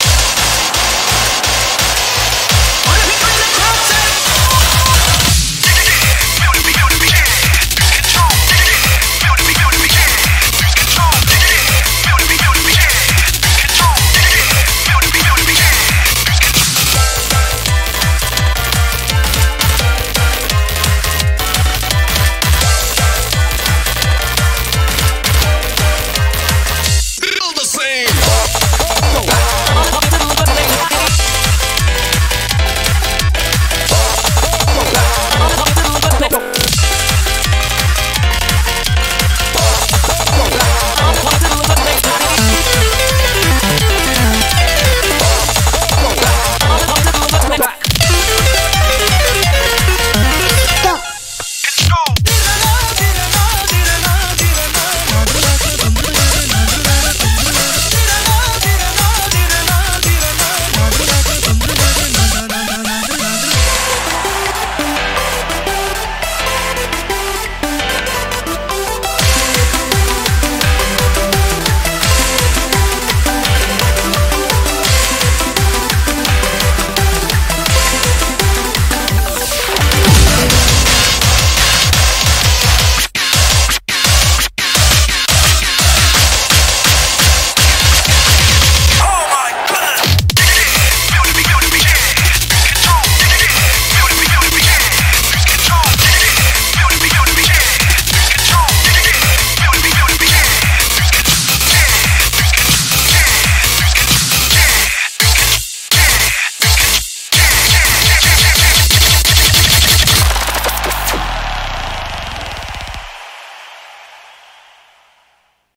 BPM170
MP3 QualityMusic Cut